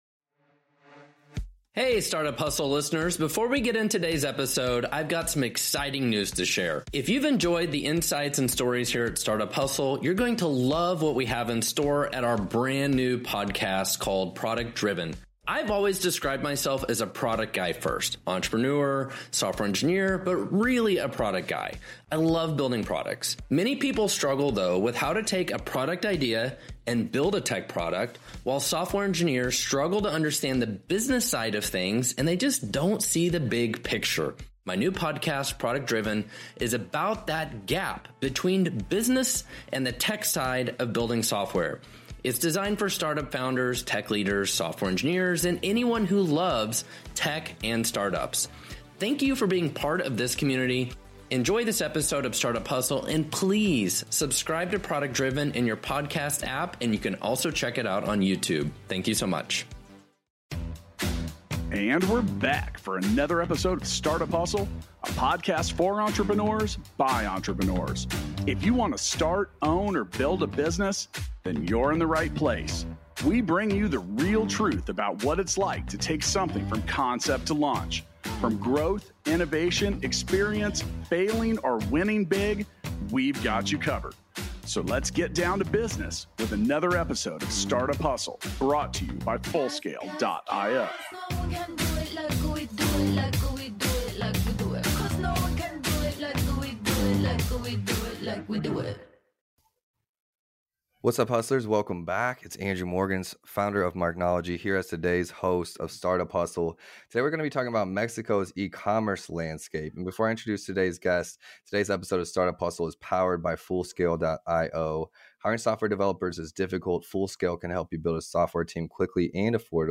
for an exciting conversation about Mexico's eCommerce landscape.